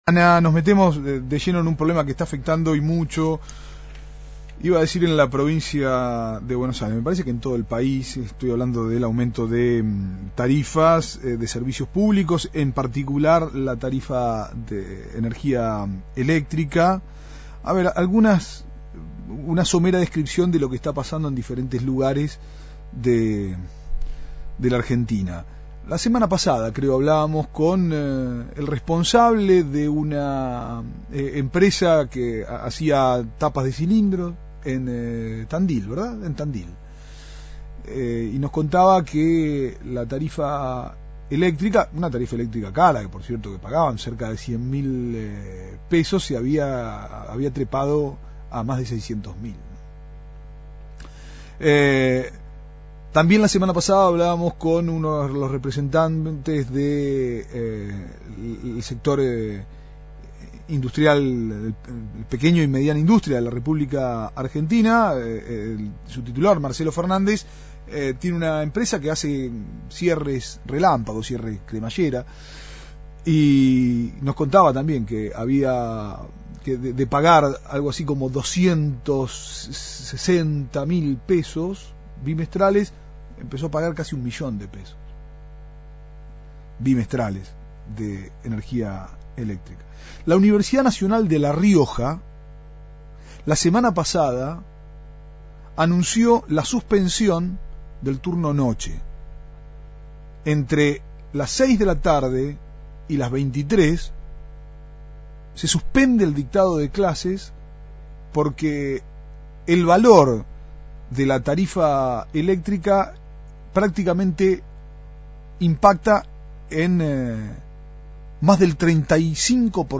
Entrevista a Federico Susbielles, Senador Prov por el FpV (6ta sección) Declaraciones anacrónicas del Intendente de Héctor Gay